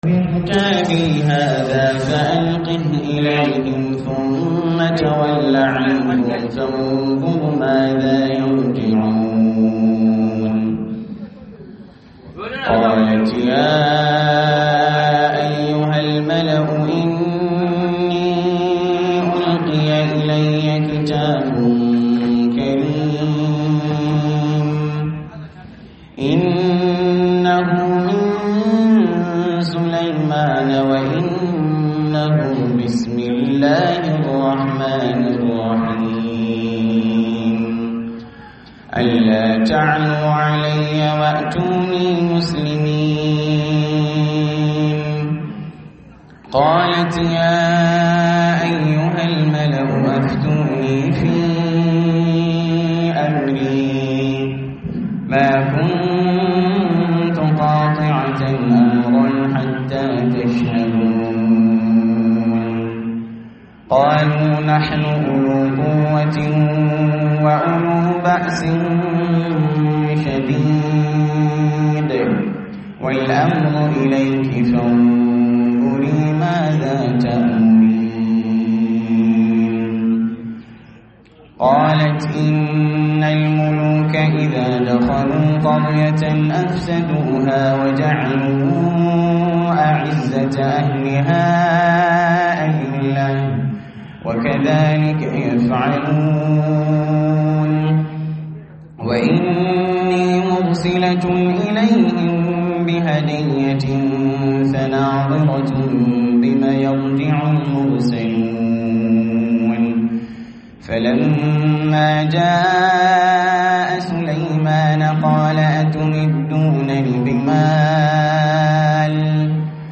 Girmama iyaye da Malamai - Muhadara